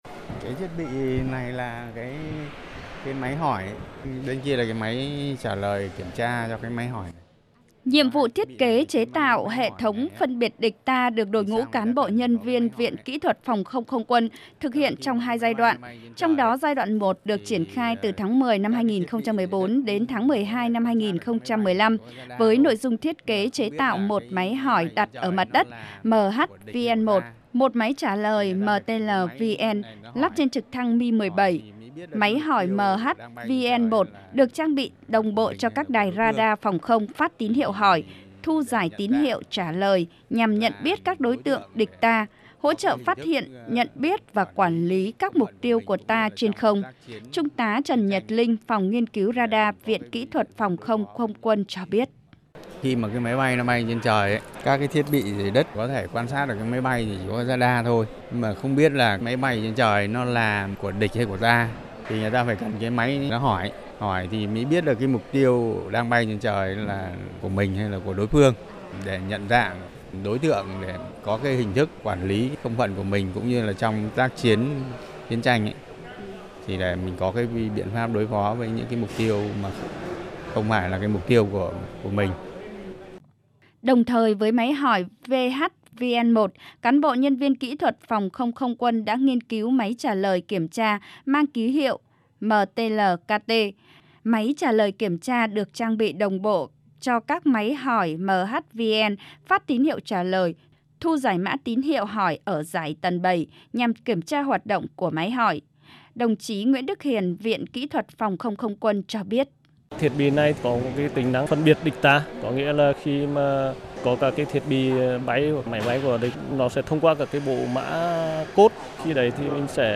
Đặc biệt đáng chú ý của hệ thống sản phẩm máy hỏi MH-VN1 và máy trả lời MTL-KT là thiết bị nhận dạng không đối không nhằm phân biệt địch-ta, đảm bảo không bắn nhầm mục tiêu trong tác chiến. Ghi nhận của phóng viên Đài TNVN: